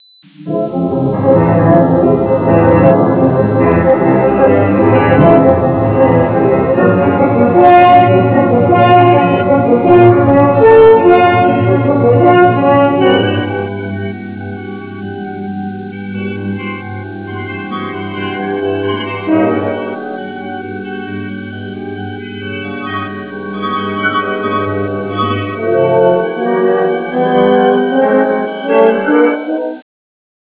una ritmata pagina musicale
Original track music